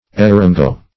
Search Result for " eryngo" : Wordnet 3.0 NOUN (1) 1. any plant of the genus Eryngium ; [syn: eryngo , eringo ] The Collaborative International Dictionary of English v.0.48: Eryngo \E*ryn"go\, n. (Bot.)